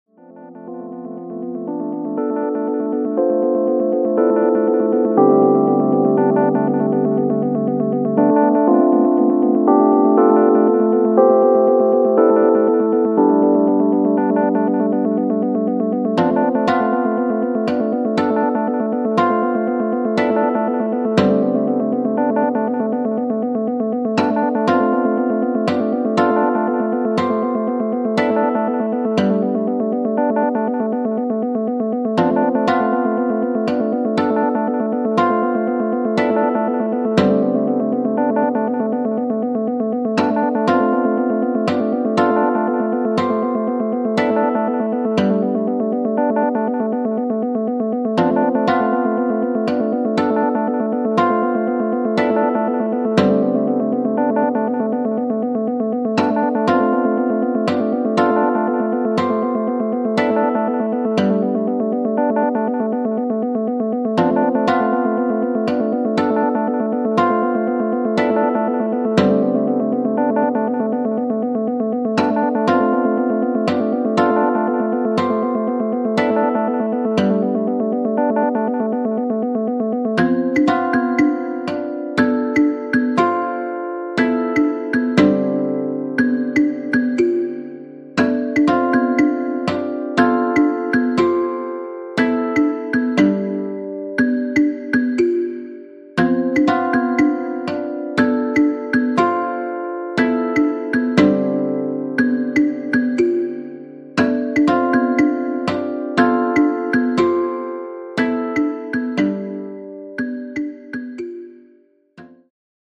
JAPANESE / TECHNO & HOUSE / NEW RELEASE(新譜)